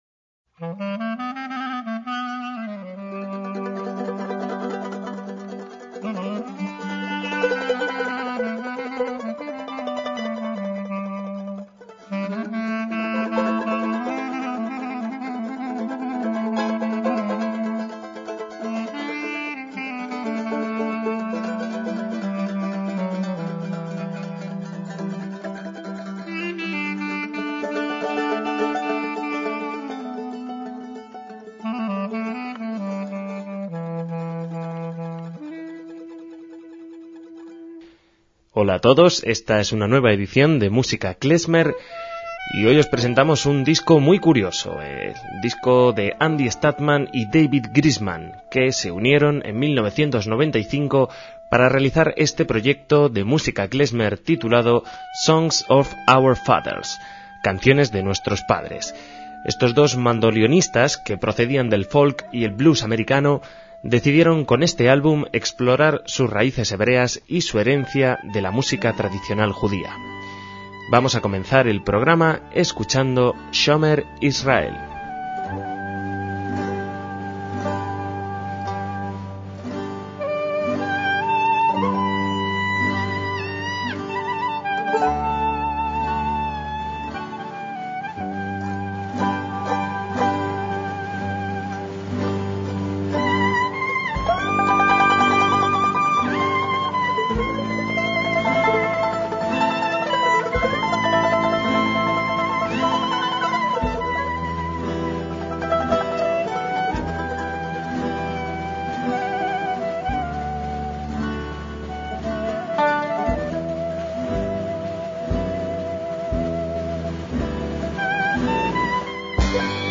MÚSICA KLEZMER
batería
contrabajo
tuba
guitarra